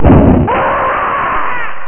bombe10.mp3